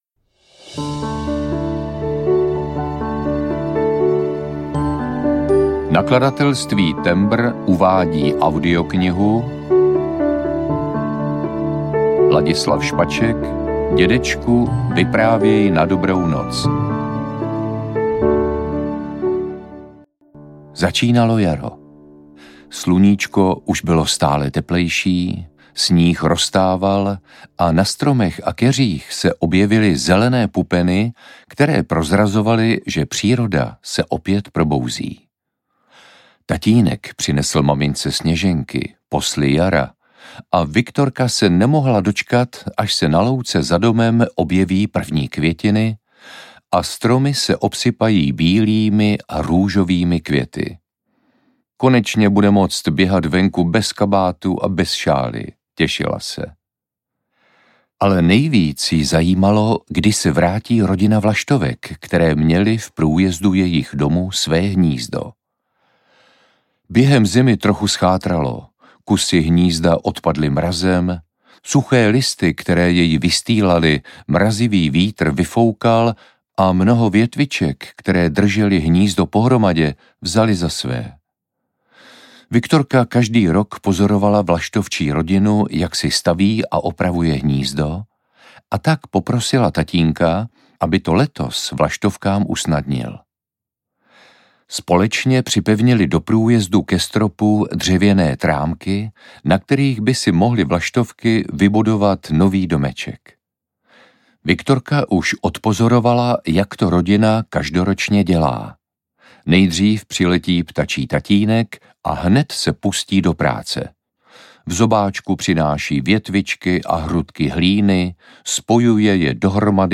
Dědečku, vyprávěj na dobrou noc audiokniha
Ukázka z knihy
• InterpretLadislav Špaček